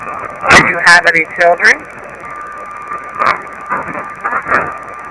EVP 8: No child